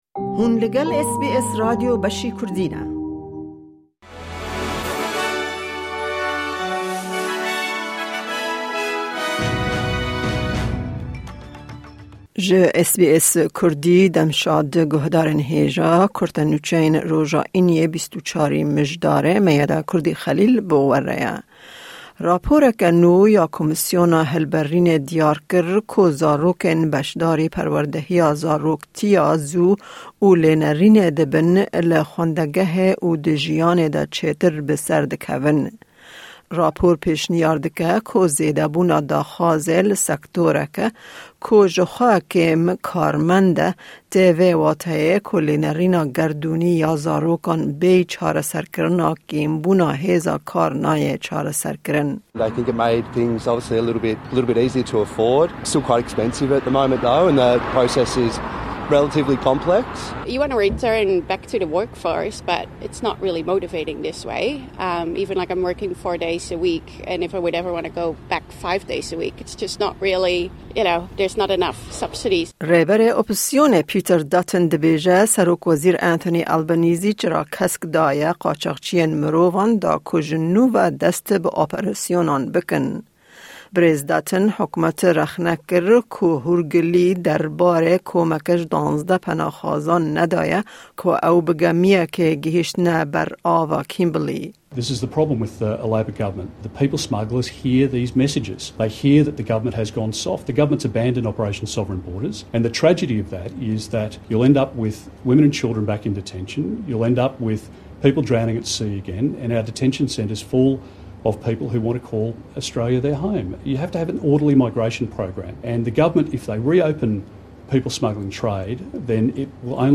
Kurte Nûçeyên roja Înî 24î Mijdara 2023